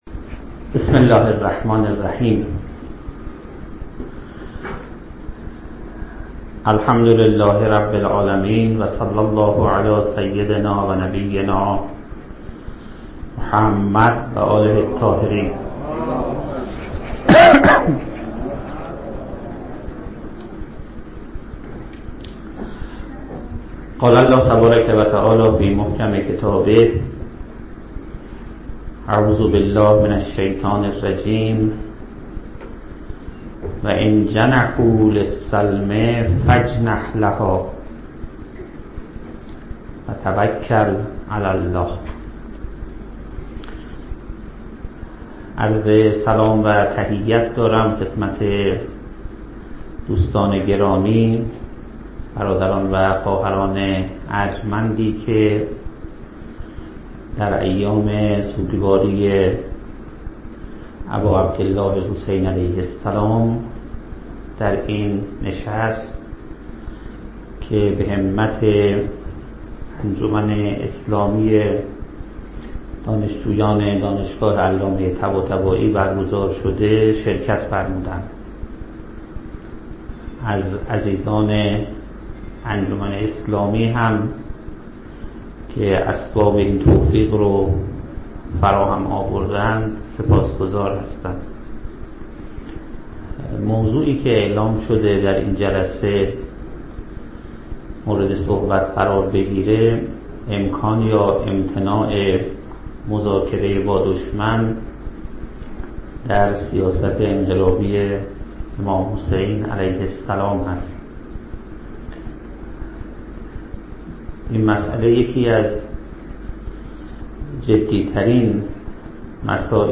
(ششم محرم ـ دانشگاه علامه طباطبایی)